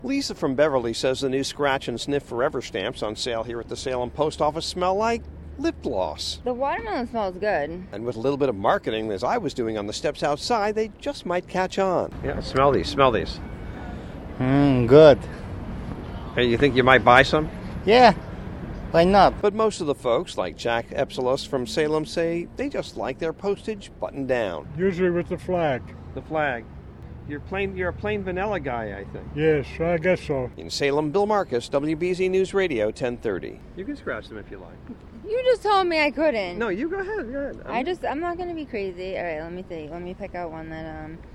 (SALEM, MA) JUN 20 –